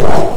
createice.wav